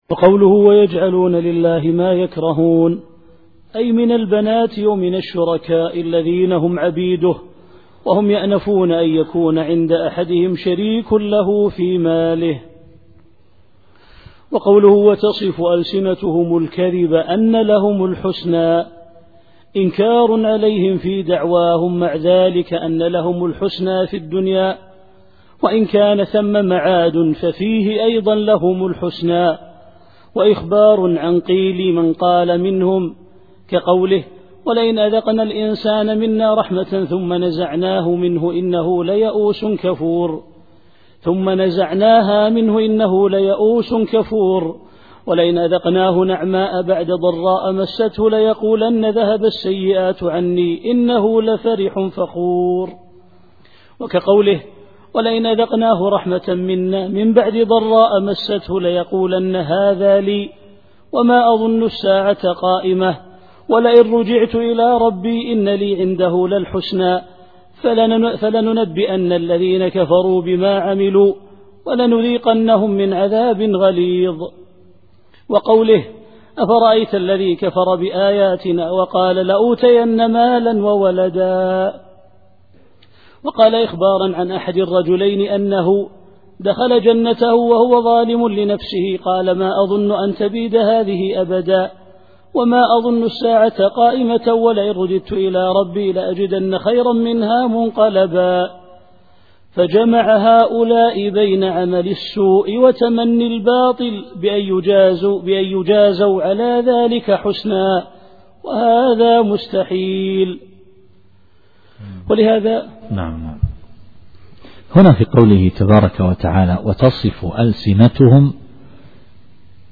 التفسير الصوتي [النحل / 62]